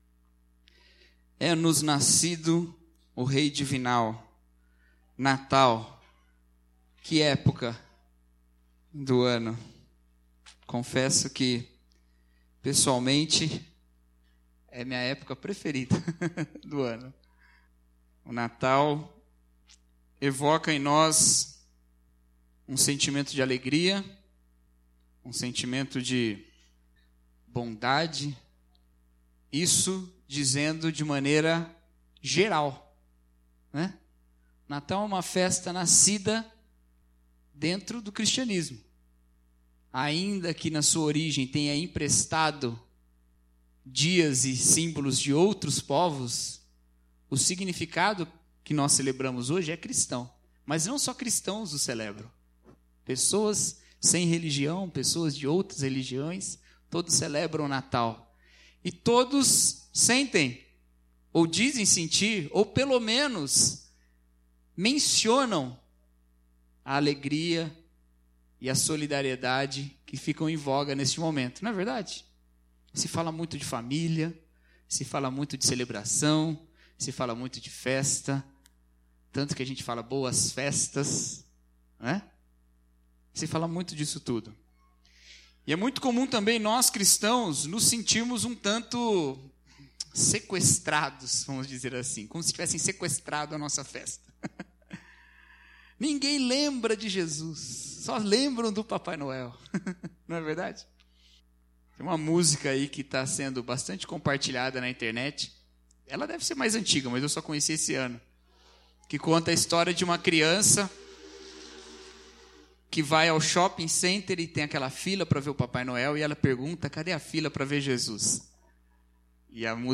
Mensagem de Natal: Encarnação